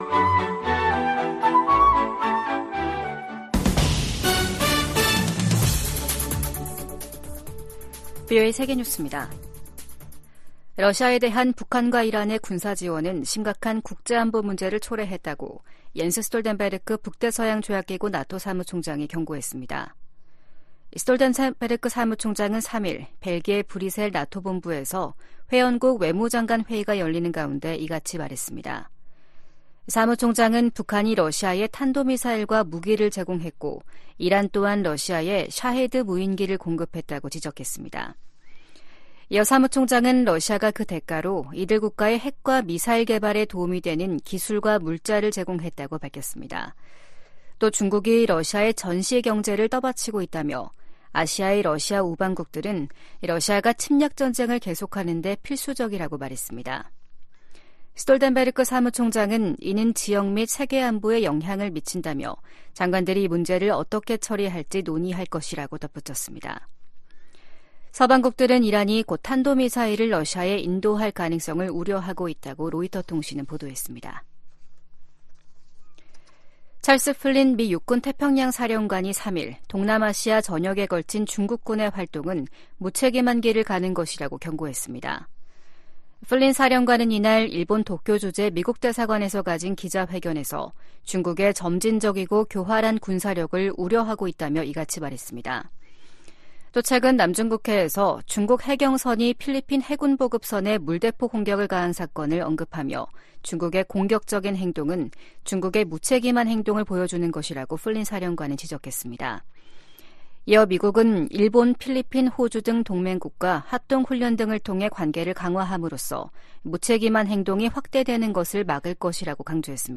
VOA 한국어 아침 뉴스 프로그램 '워싱턴 뉴스 광장' 2024년 4월 4일 방송입니다. 북한이 신형 중장거리 고체연료 극초음속 탄도미사일 시험발사에 성공했다고 대외 관영매체들이 보도했습니다. 북한이 보름 만에 미사일 도발을 재개한 데 대해 유엔은 국제법 위반이라고 지적했습니다.